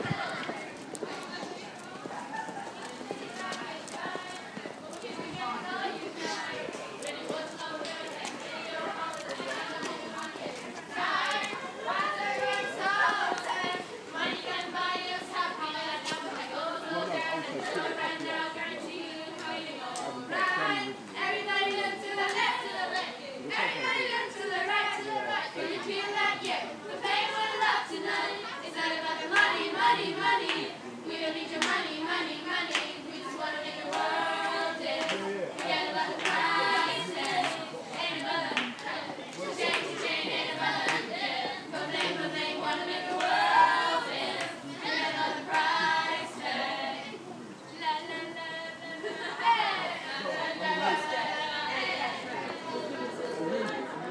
Cheerful singing at Blackfriars station